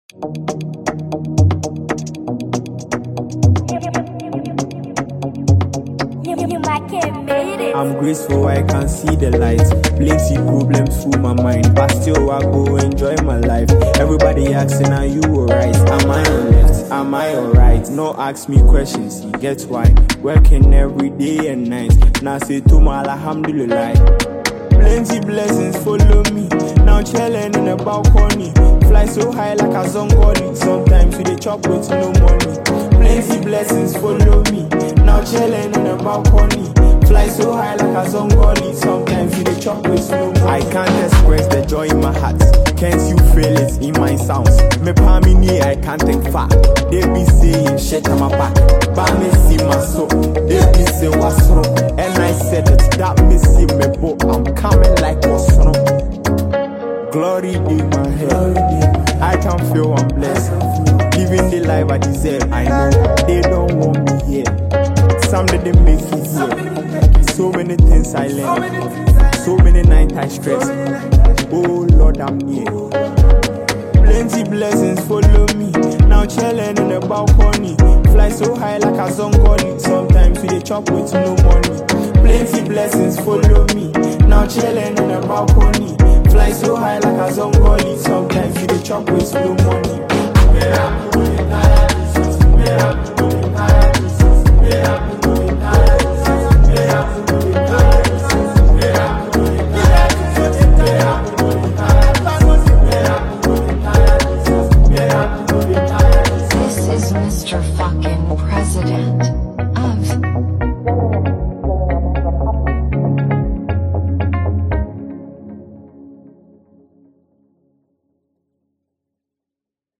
gospel
inspirational single